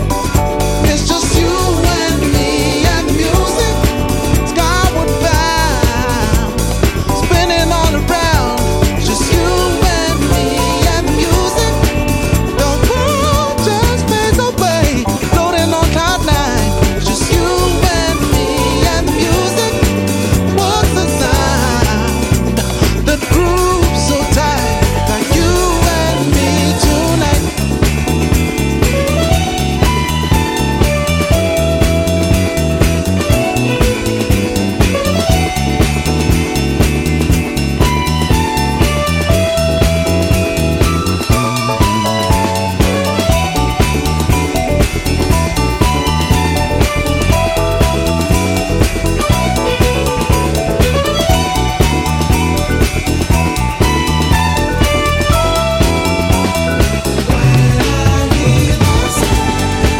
それぞれ8分の尺で温かいグルーヴのブギー/ファンクを展開